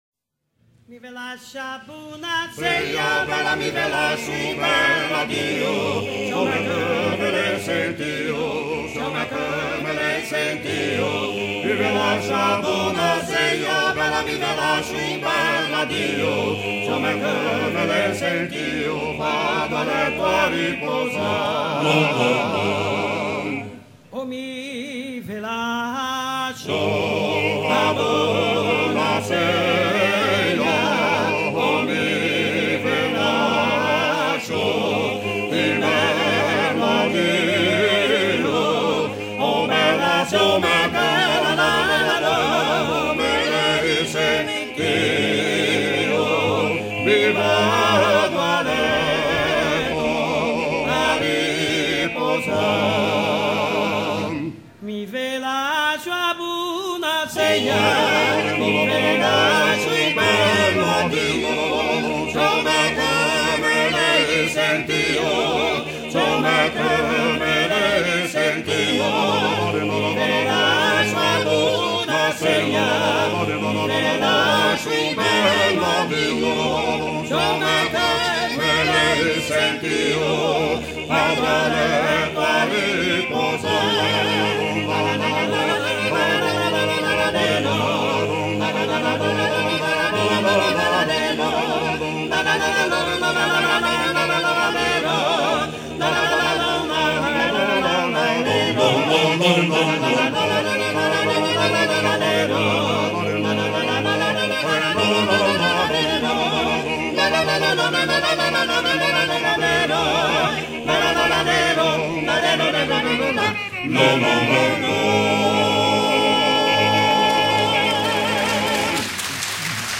Groupe originaire de Gênes, en italie
Pièce musicale éditée